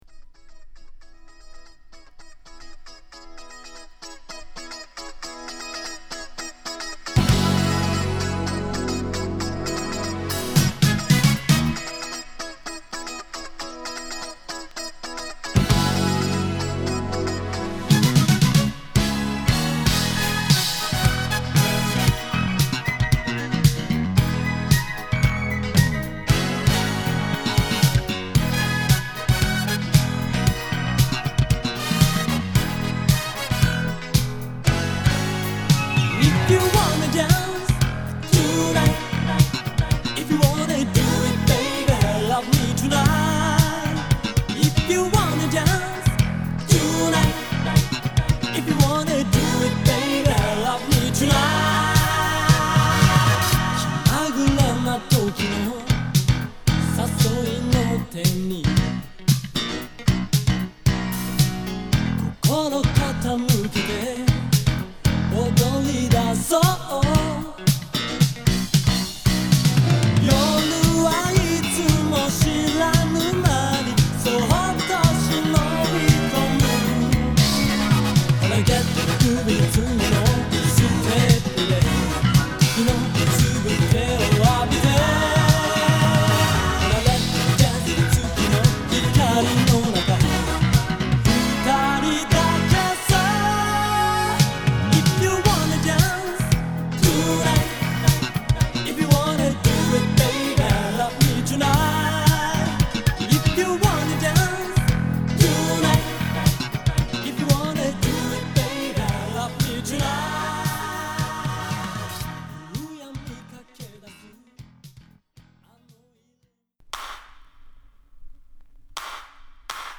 シティ・ポップ／和製ブギーを代表するシンガーソングライター／プロデューサー
昼の海辺から都会の夜にシーンを変えて、アーバンブギーA1
太いベースと綺羅びやかなシンセのブギートラックにラップも絡めたB1
抜けの良いディスコビートとファンクなベースが支えるB2